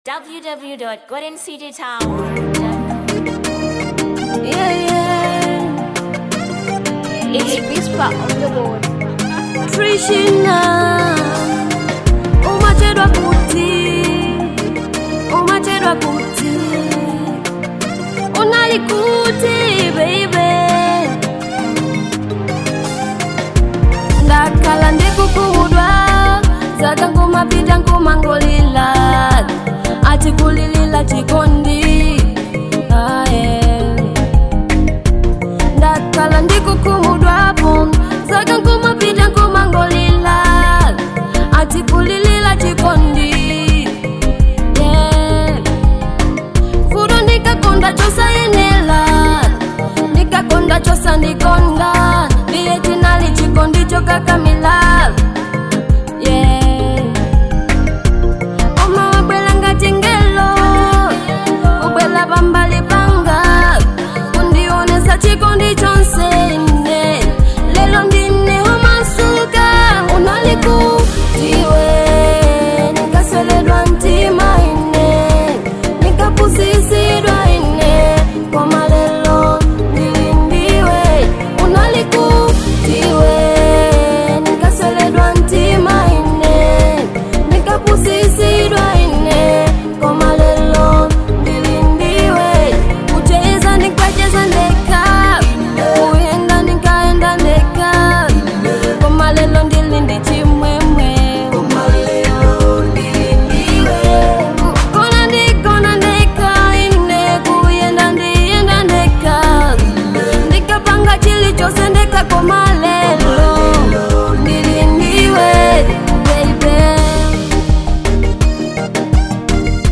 2. Afro Pop